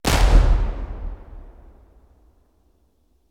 Cannon Fire 10S Cannon 03.wav